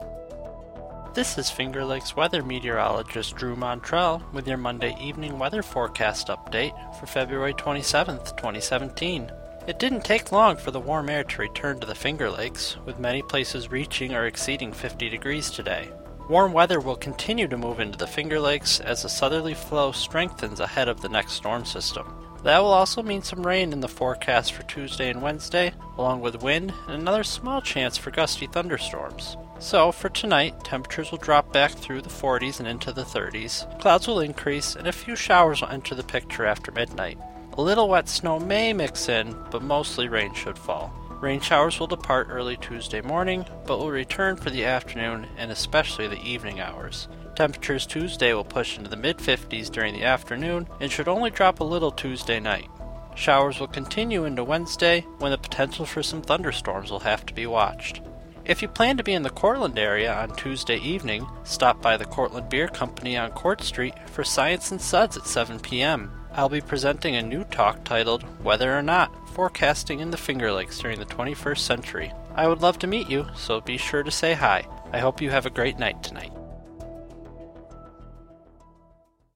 Evening Weather Forecast: February 27 transcript